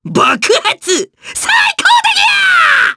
Lakrak-Vox_Skill4_jp_b.wav